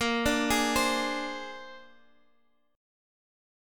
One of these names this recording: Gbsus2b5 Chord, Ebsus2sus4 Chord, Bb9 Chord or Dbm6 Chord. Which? Bb9 Chord